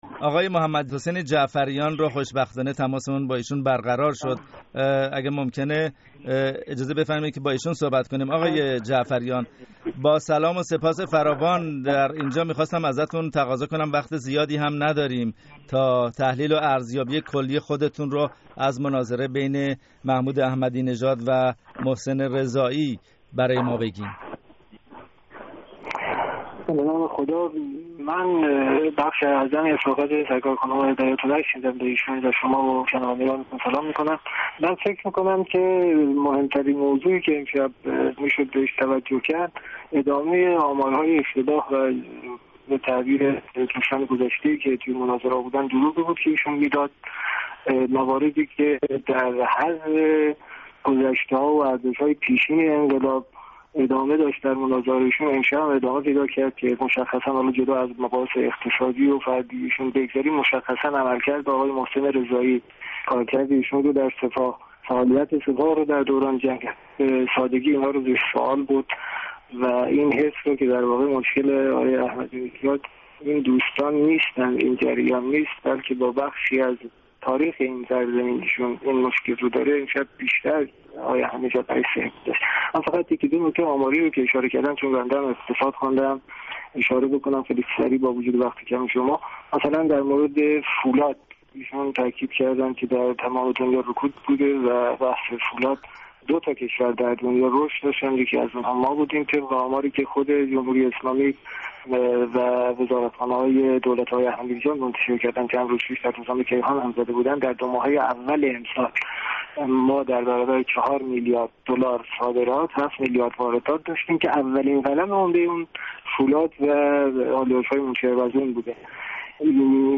از تحلیلگران نزدیک به جریان اصولگرا در باره مناظره رضایی - احمدی نژاد (بخش دوم)